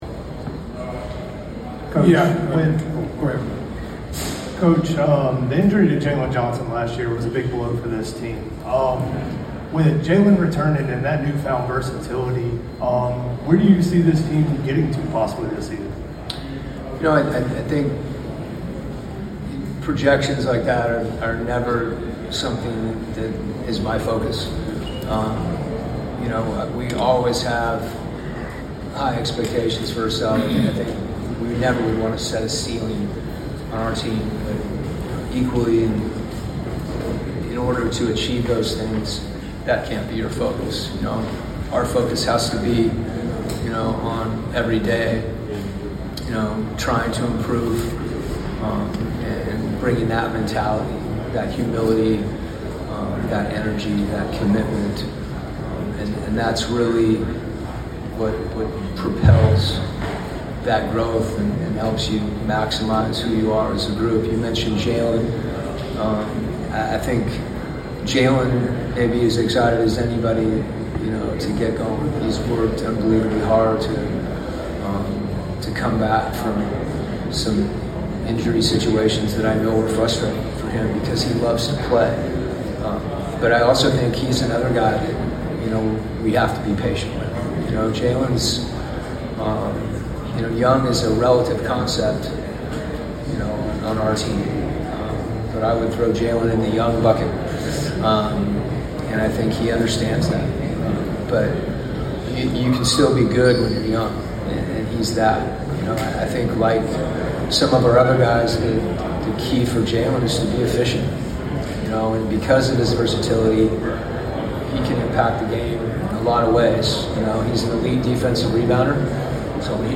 Atlanta Hawks Coach Quin Snyder 2025 Media Day Press Conference at PC&E.